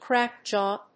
アクセントcráck‐jàw